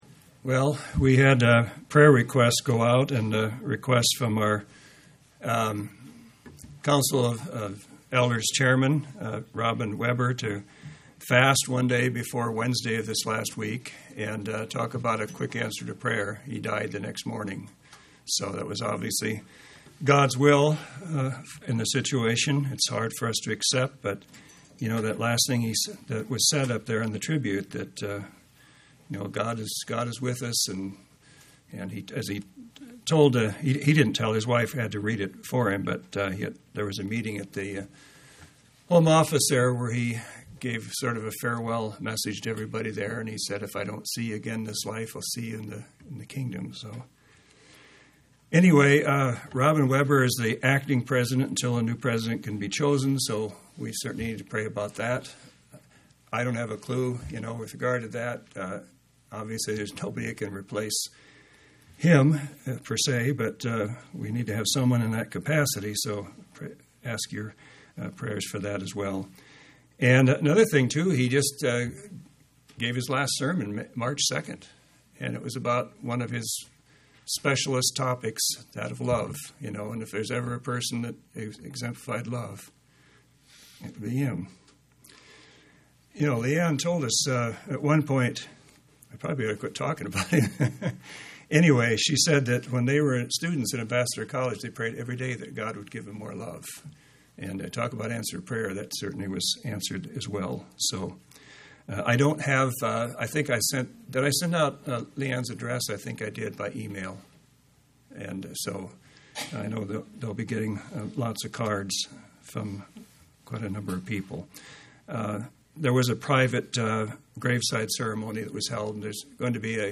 Given in Central Oregon
UCG Sermon Studying the bible?